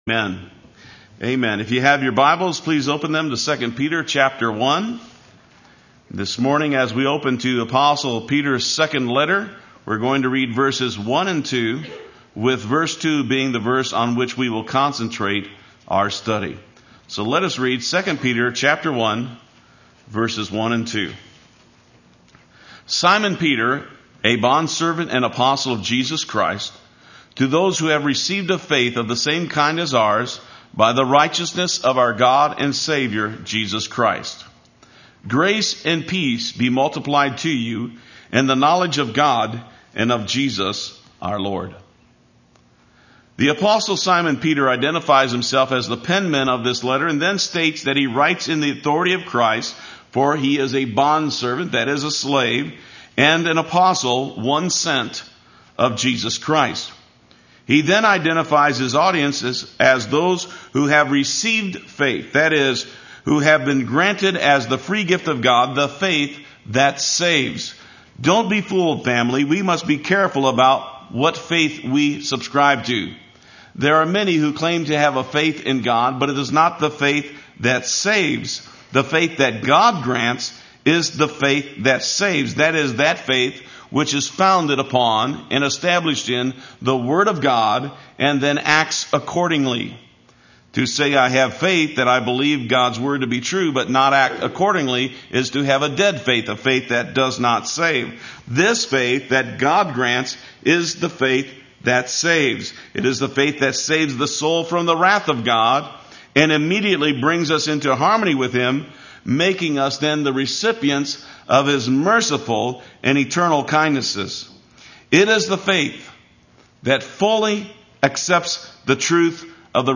Play Sermon Get HCF Teaching Automatically.
Sunday Worship